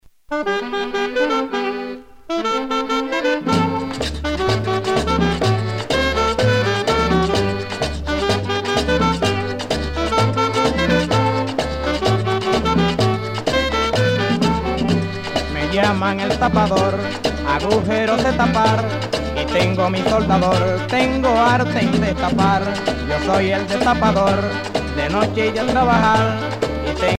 danse : merengue
Pièce musicale éditée